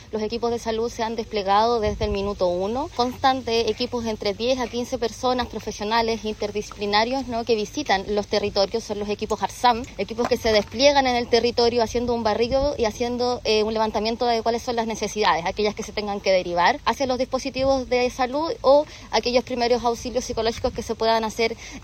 Por su parte, la seremi de Salud, Javiera Ceballos, explicó que el plan contempla la presencia de distintos Equipos de Apoyo a la Respuesta en Salud Mental, con atención psicológica y derivación a la red asistencial en los casos que lo requieran.